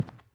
Sounds / sfx / Footsteps / Carpet
Carpet-01.wav